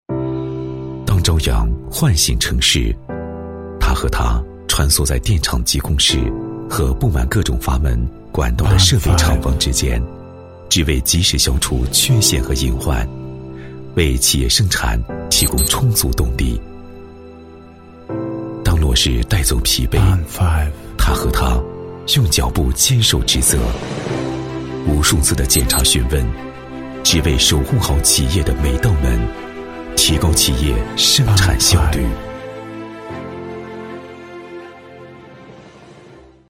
男9-人物解说《链上铝行 奋斗有我》-磁性低沉
男9-低沉磁性 质感磁性
男9-人物解说《链上铝行 奋斗有我》-磁性低沉.mp3